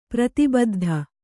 ♪ prati baddha